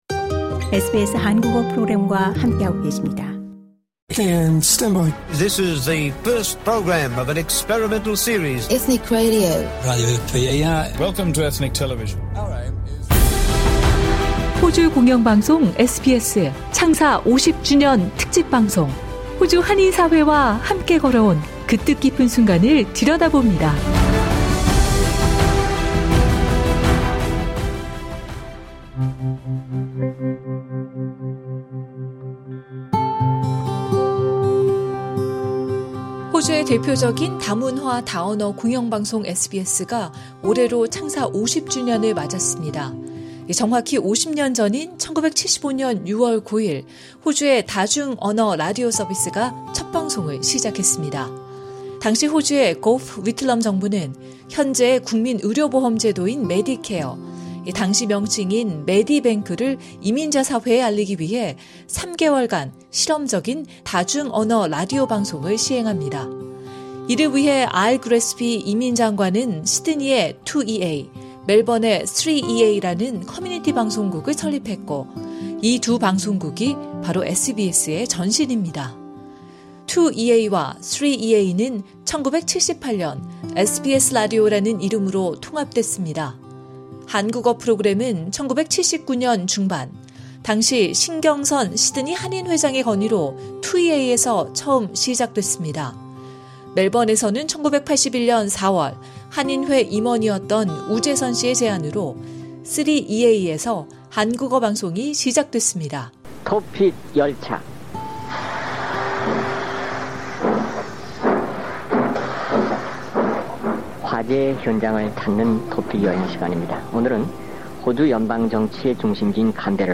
고령으로 청력을 잃으신 관계로 이번 인터뷰는 서면 질문지를 보고 답변을 하는 방식으로 진행됐습니다.